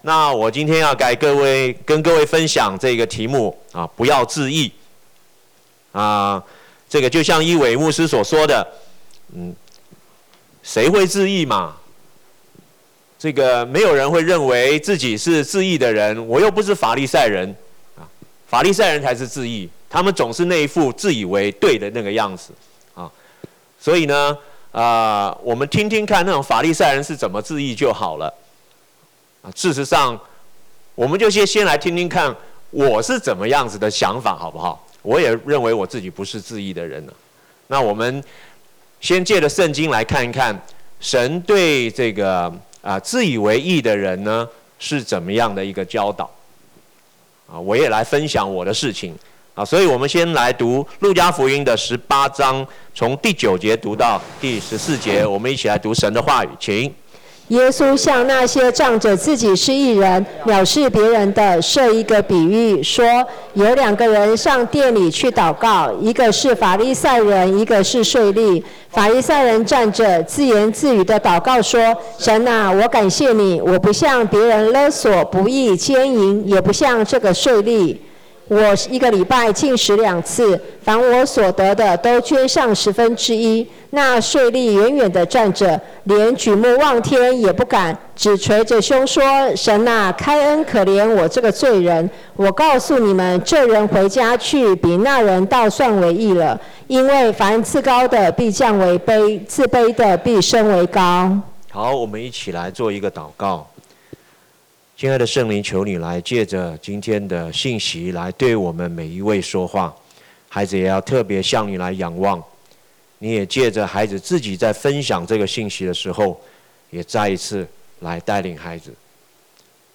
講道筆記